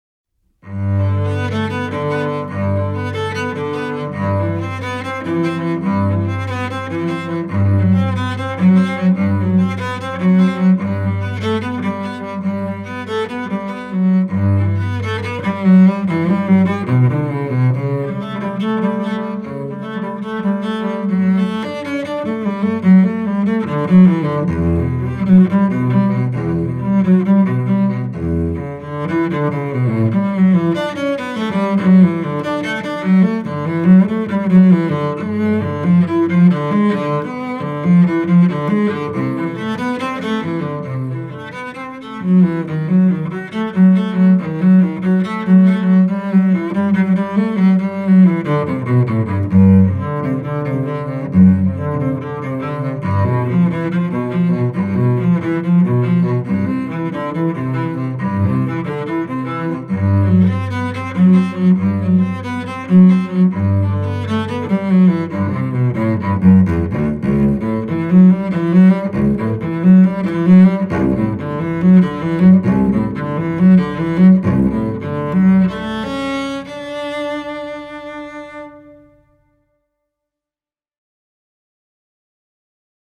Solo cello Tracks recorded at FTM Studio in Denver, Colorado
Bach Cello Suite
Cello Solo Suite 1 Prelude2.mp3